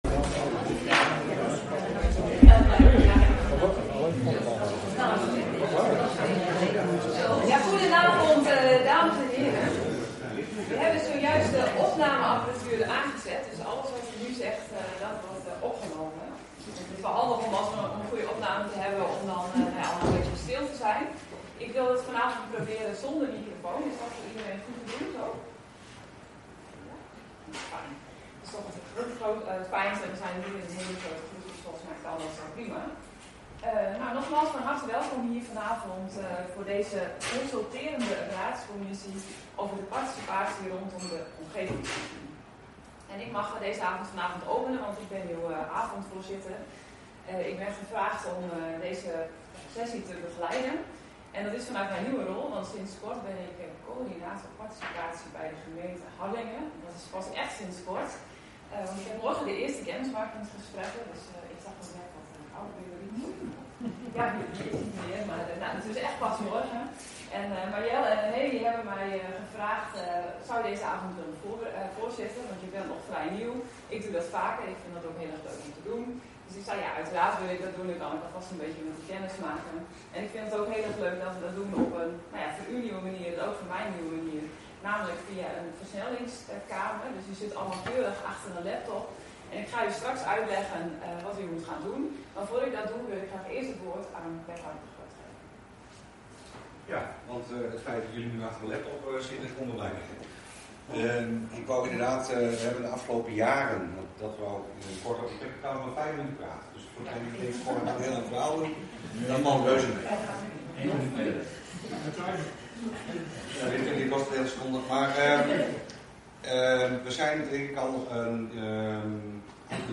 Agenda Harlingen - Openbare consulterende raadscommissie woensdag 14 mei 2025 18:00 - 21:30 - iBabs Publieksportaal